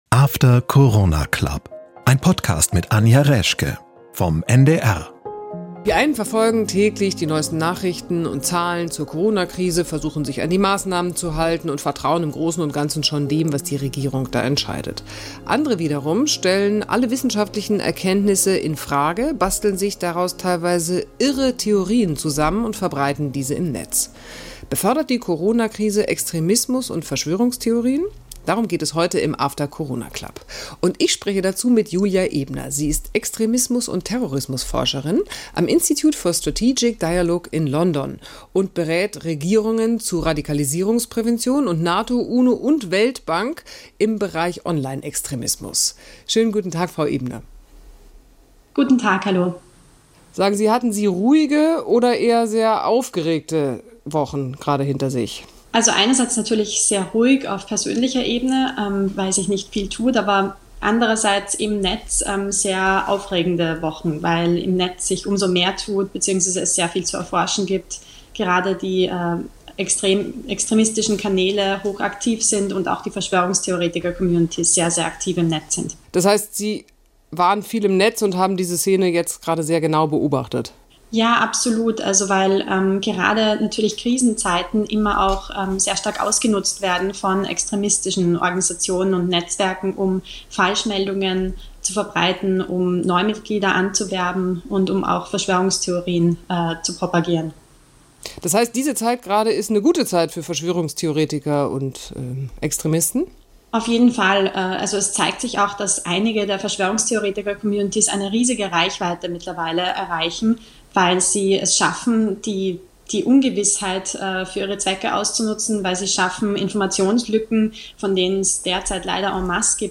Sie schaffen es Informationslücken mit Falschmeldungen zu füllen, meint sie im Gespräch mit Anja Reschke im After Corona Club.